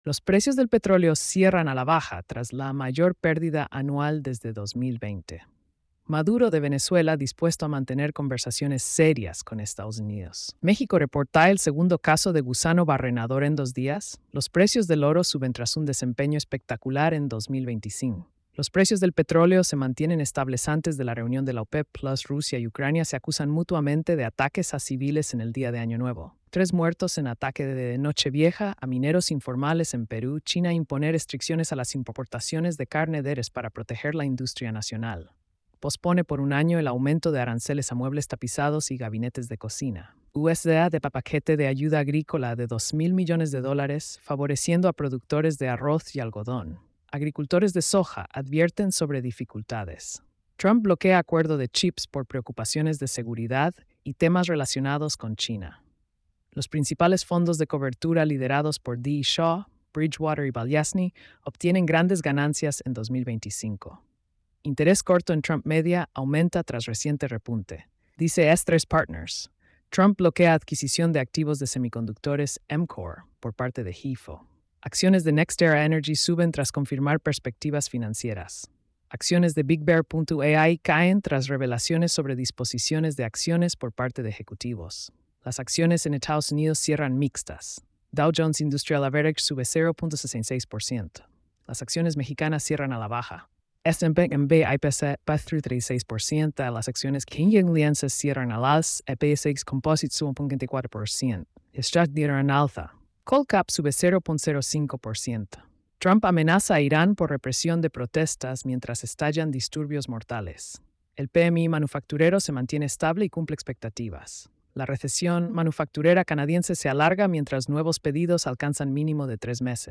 🎧 Resumen Económico y Financiero.